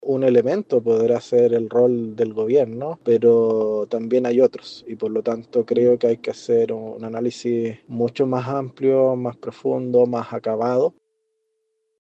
Por su parte, el diputado Raúl Soto (PPD) instó a cada colectividad a realizar un proceso de reflexión y autocrítica considerando las múltiples causas del fracaso electoral.